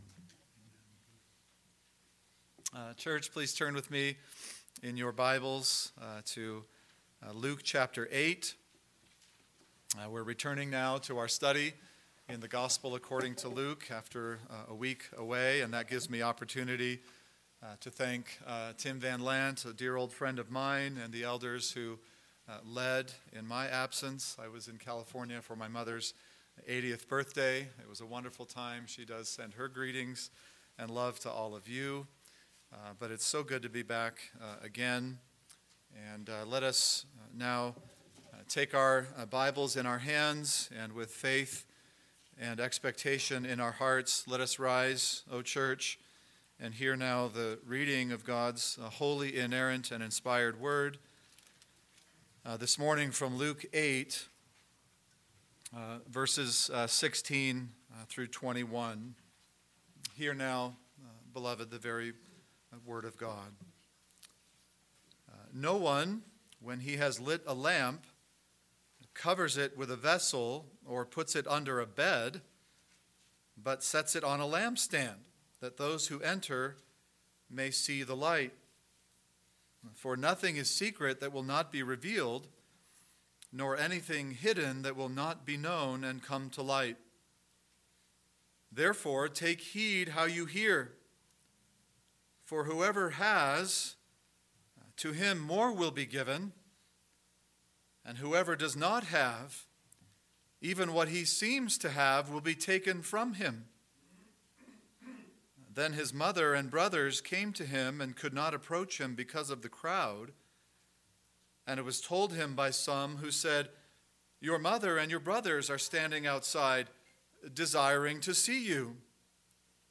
AM Sermon – 11/24/2019 – Luke 8:16-21 – Beware! Beware! Beware!